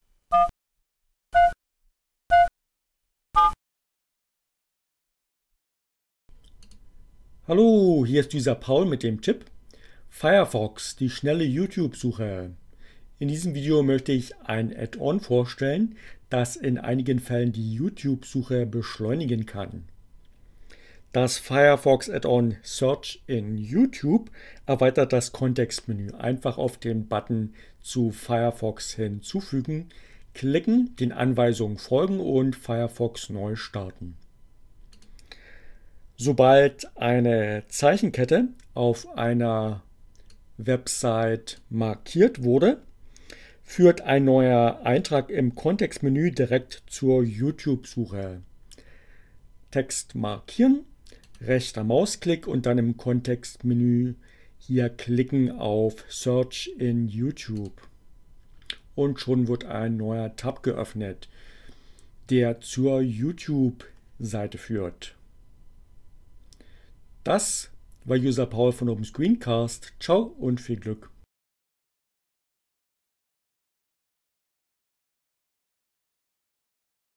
Tags: CC by, Linux, Neueinsteiger, ohne Musik, screencast, Firefox